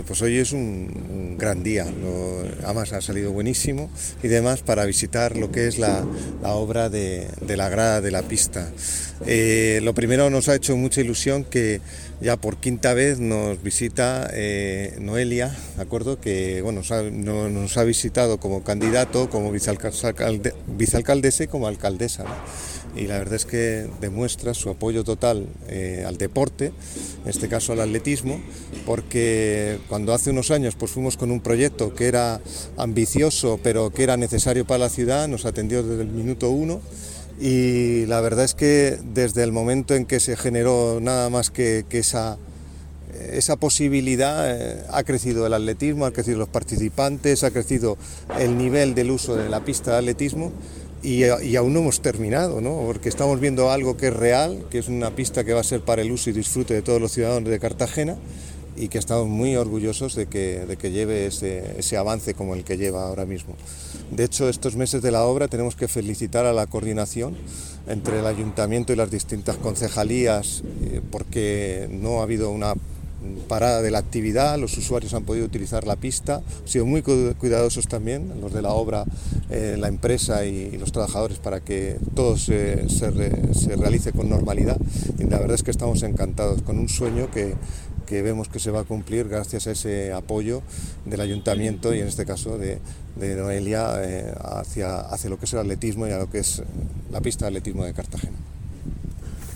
Enlace a Declaraciones de la alcaldesa Noelia Arroyo